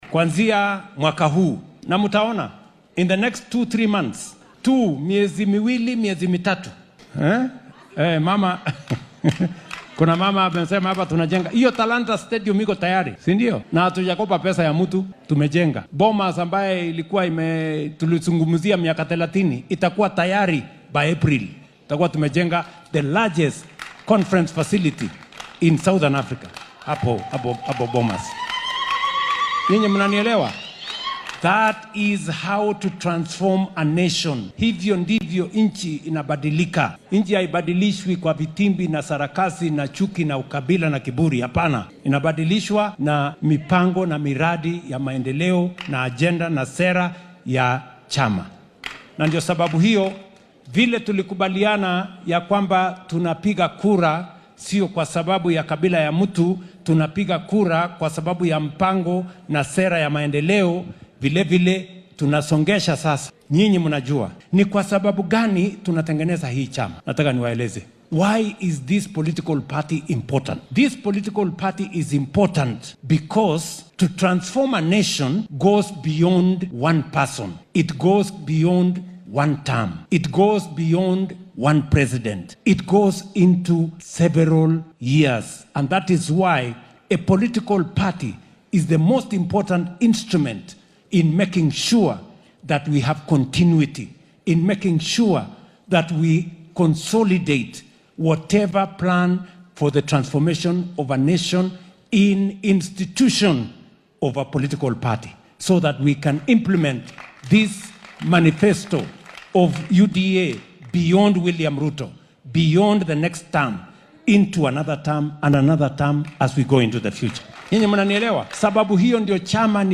Madaxweyne William Ruto ayaa sabtida maanta ah ku martigaliyay hoggaamiyayaasha xisbiga UDA ee ismaamulka Nyeri aqalka yar ee madaxtooyada Sagana State Lodge